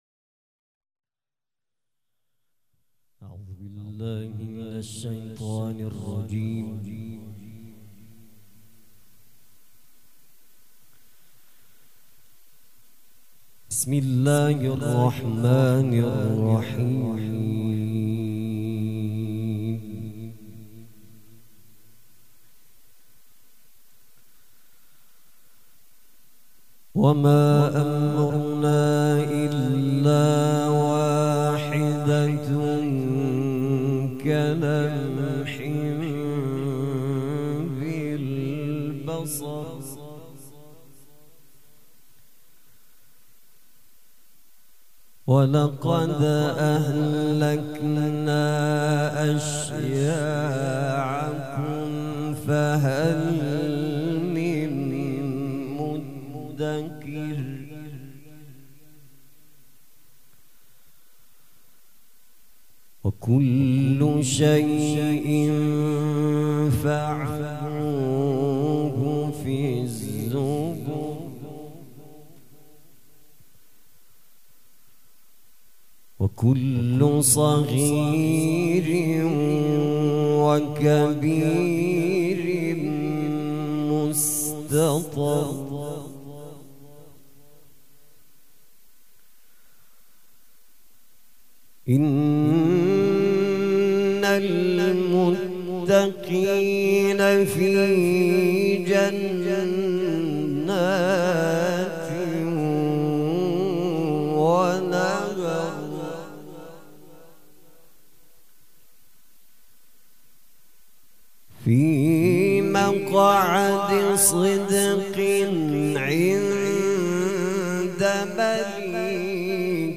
قرائت قرآن
قرائت قرآن کریم
مراسم عزاداری شب شام غریبان